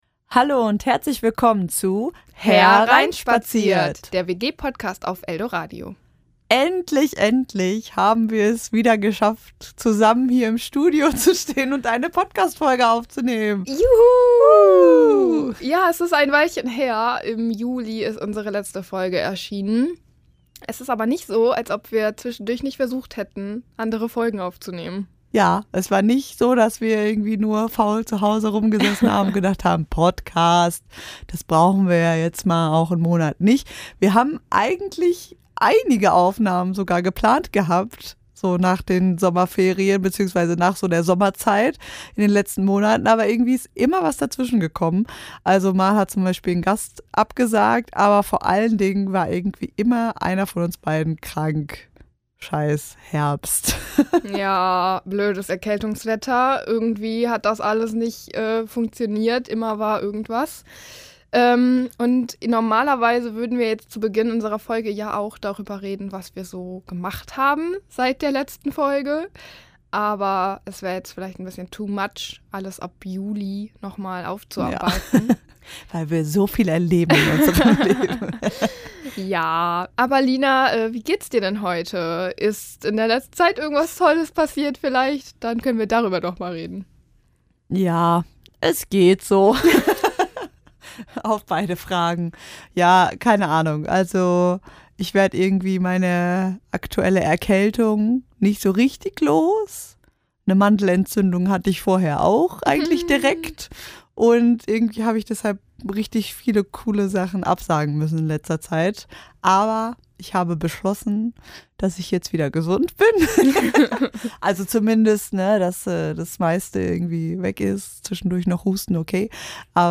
In dieser Folge stellen die beiden ihre liebsten Spiele für Silvester vor, von Krimidinner bis „Verdammt nochmal!“. Und sie testen sogar live ein neues Spiel aus.